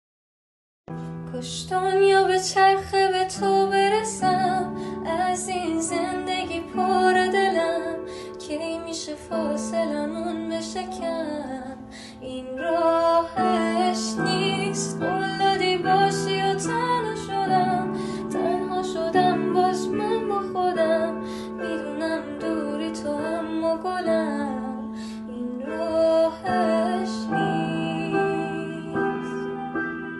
پاپ غمگین عاشقانه